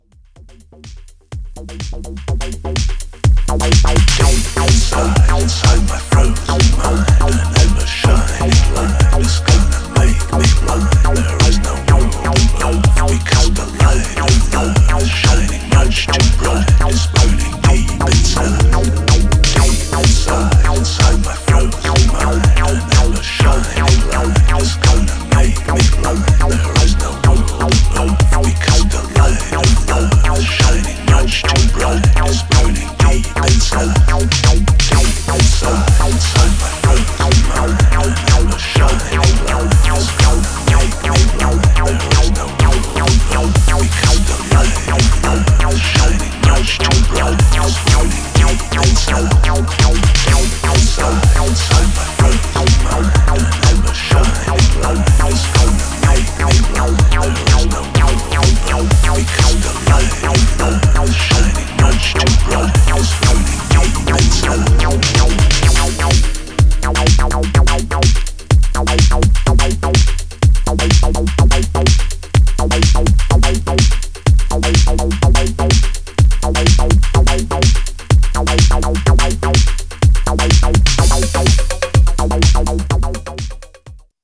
Styl: Electro, House, Breaks/Breakbeat